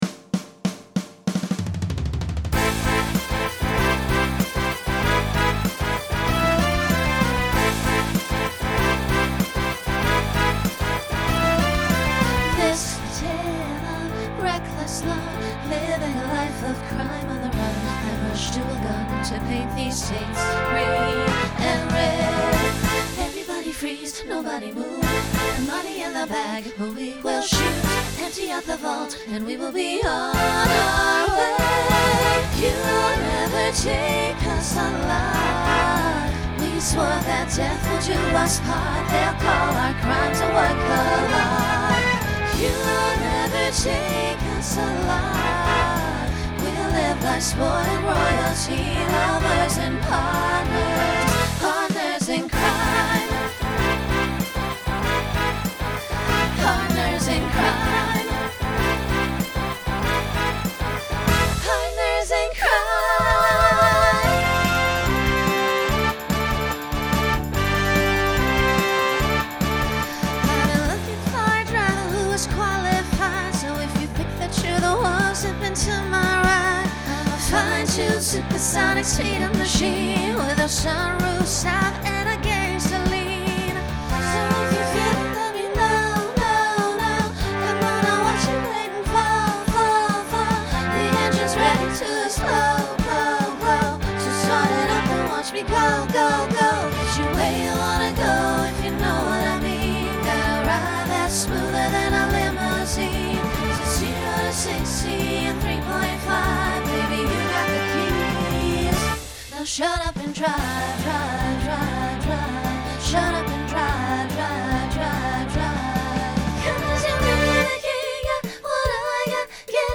Genre Pop/Dance , Rock Instrumental combo
Voicing SSA